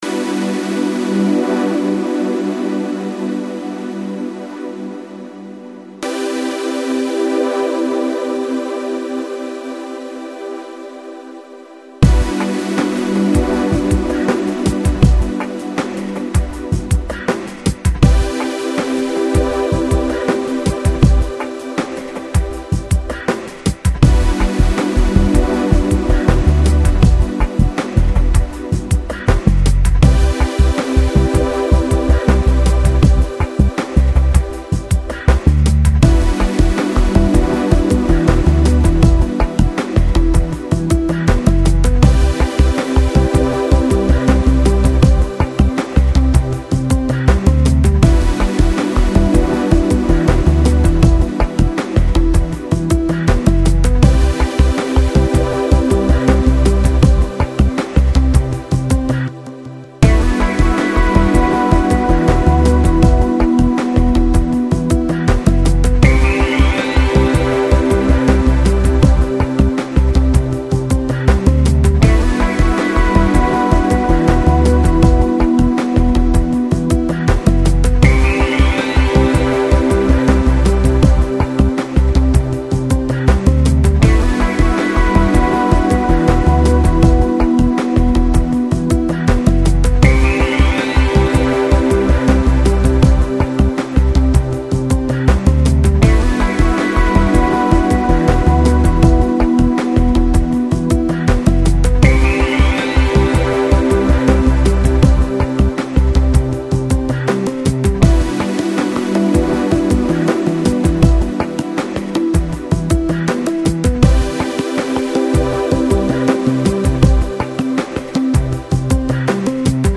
Lounge, Chill Out, Downtempo, Balearic